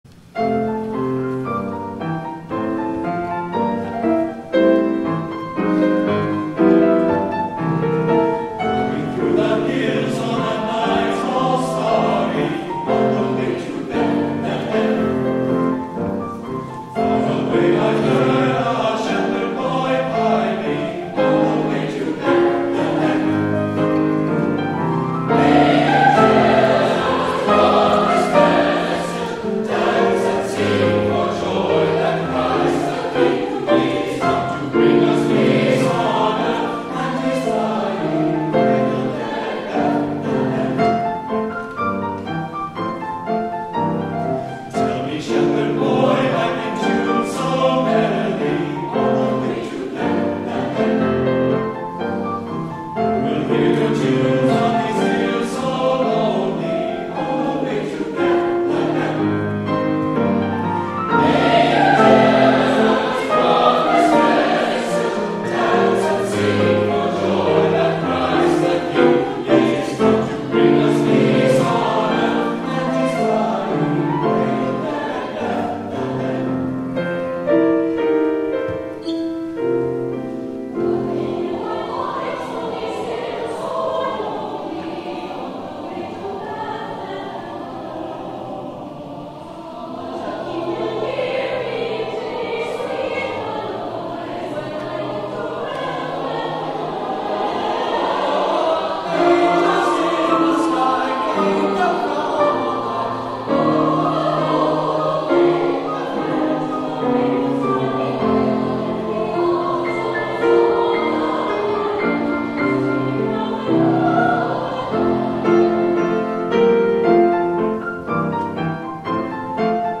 8 P.M. WORSHIP
THE CAROL